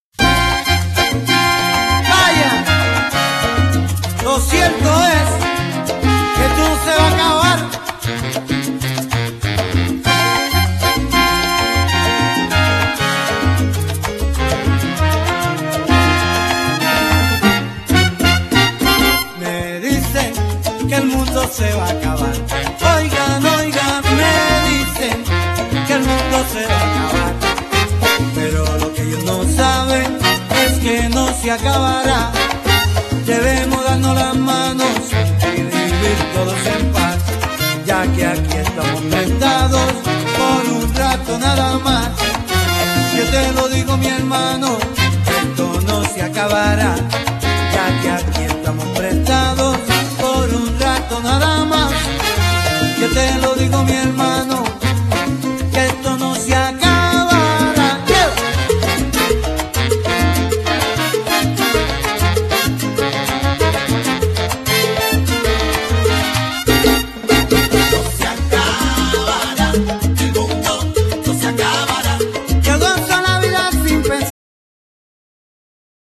Genere : Latin